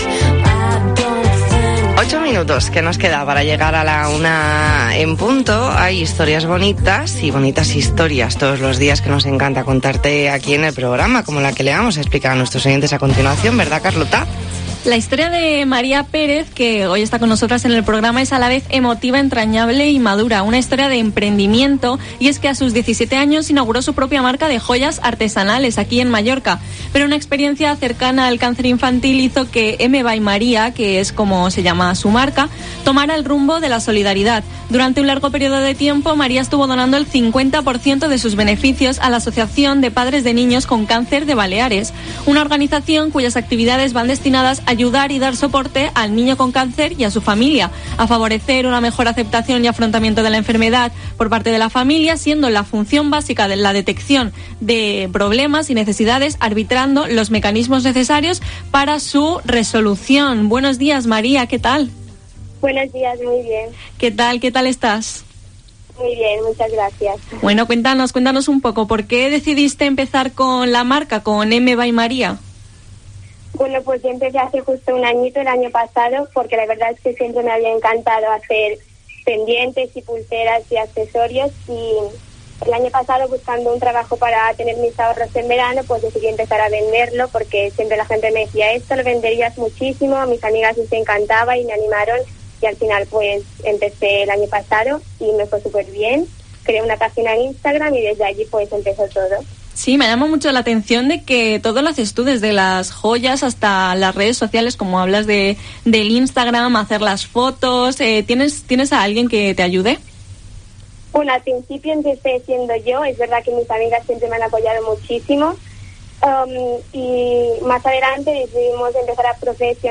Entrevista en La Mañana en COPE Más Mallorca, miércoles 7 de julio de 2021.